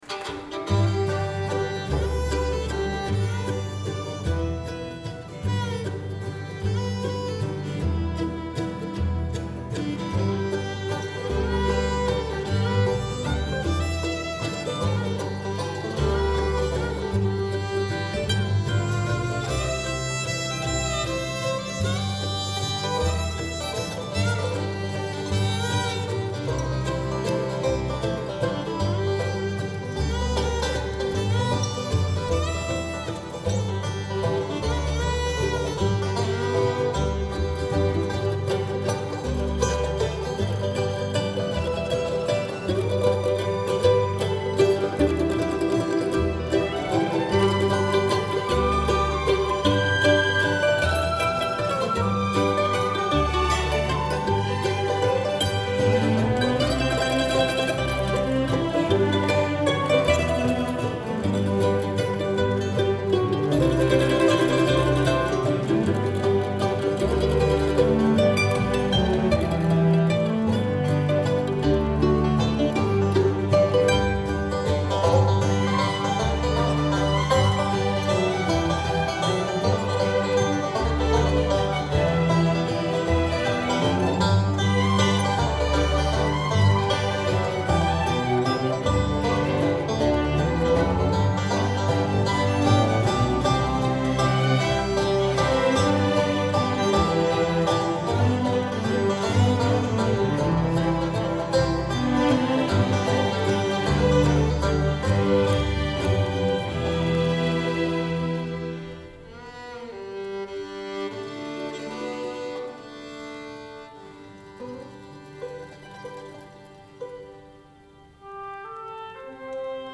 Theme: Americana, 4th of July
Ensemble: Full Orchestra, Chamber Orchestra
Movement 2: